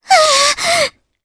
Mirianne-Vox_Damage_jp_01.wav